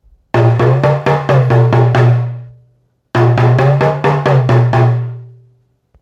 新型 トーキングドラム タマン タムタム アフリカ民族楽器 （p675-27） - アフリカ雑貨店 アフロモード
脇に挟んで付属の湾曲した棒で叩いて演奏します。
脇でテンションを調節することで音を変幻自在に操ることができます。太鼓とは思えないほど豊かな音色を出します。
説明 この楽器のサンプル音 原産国 材質 木、革、ナイロンロープ サイズ サイズ：幅：21cm（ヘッド部：19cm） 長さ：50cm 重量 2.1kg コメント 写真のスティック付。